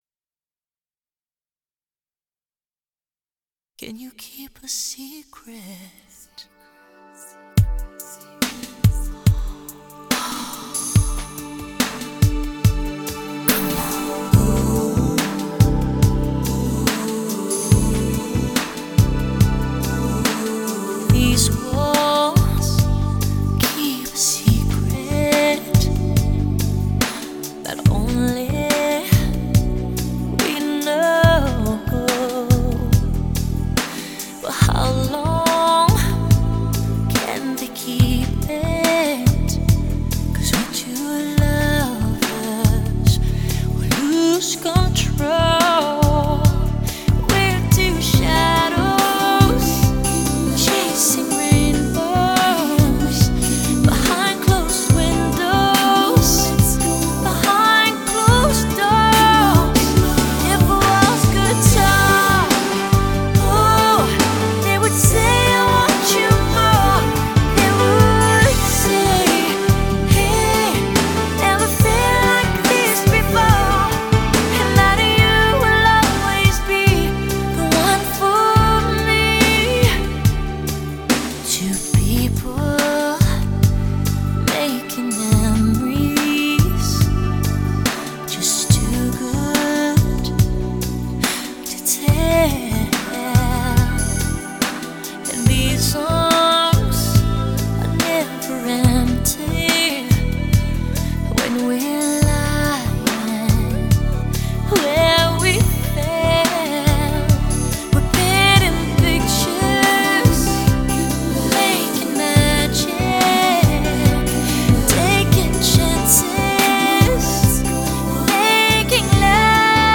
2周前 欧美音乐 11